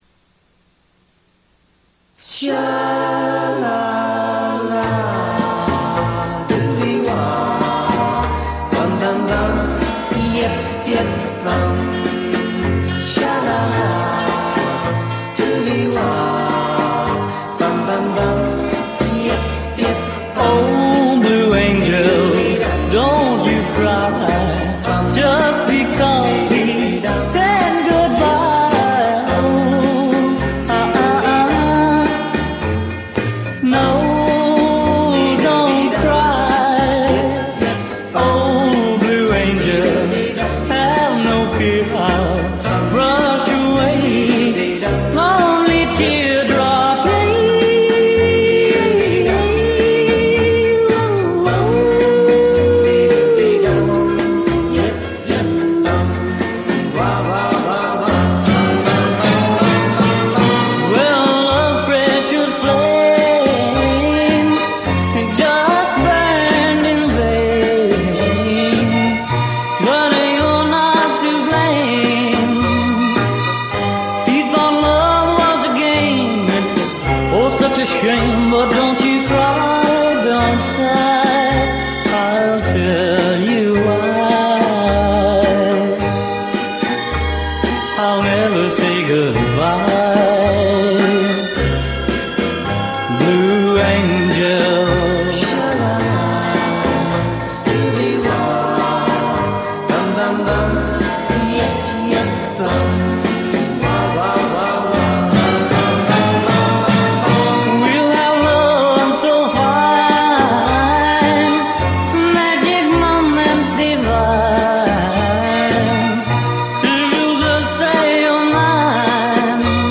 very pretty song.